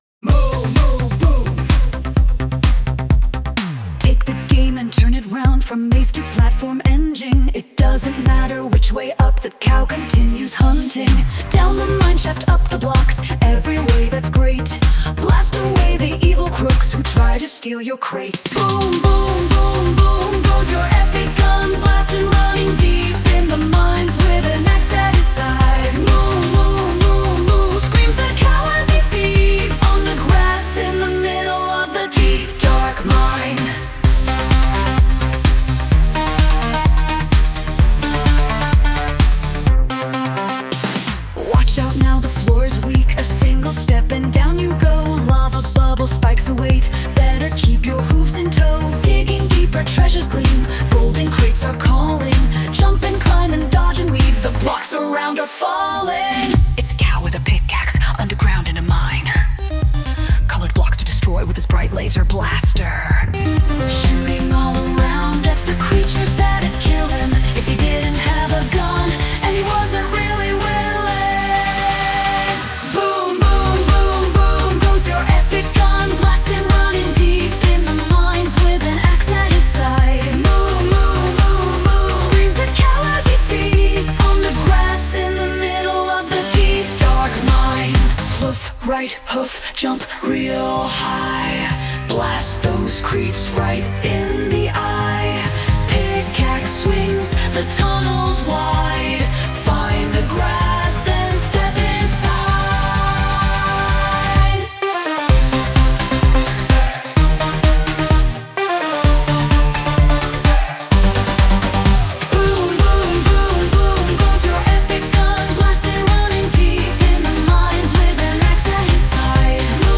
Lyrics : Half me, Half ChatGPT
Sung by Suno